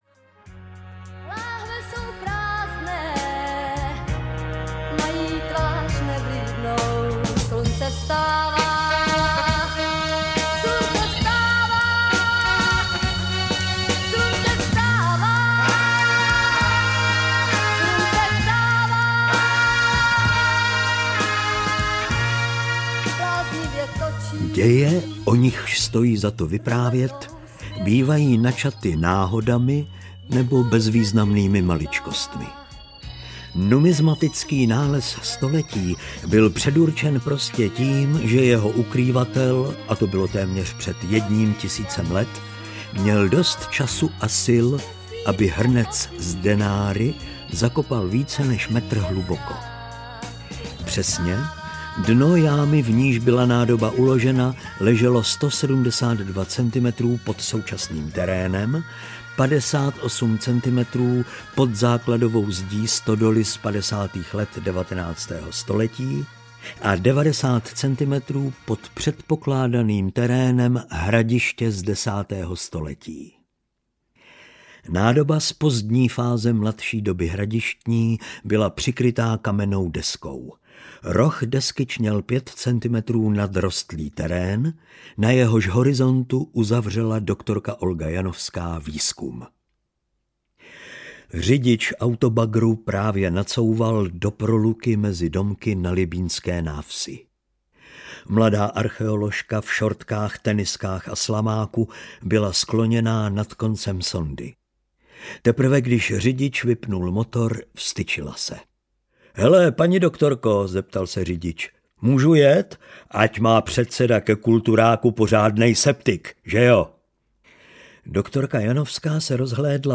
Denár v dívčí dlani audiokniha
Ukázka z knihy